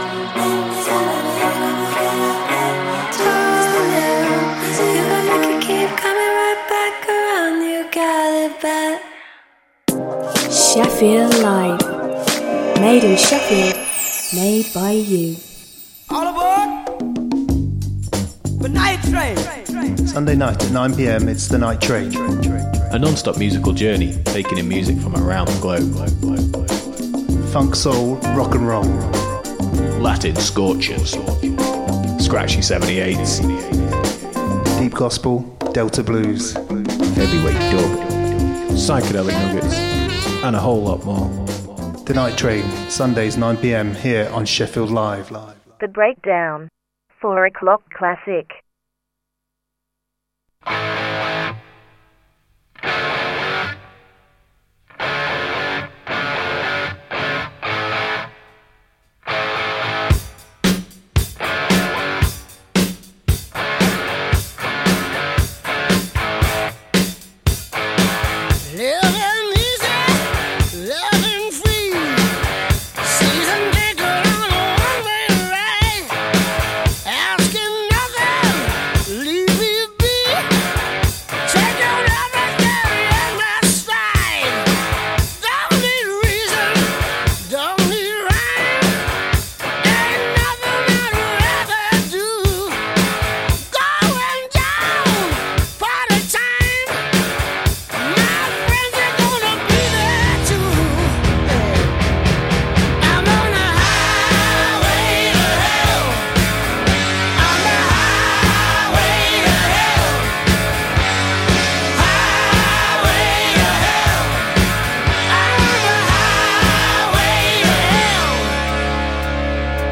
A weekly musical jamboree transmitting live to the world every Saturday afternoon.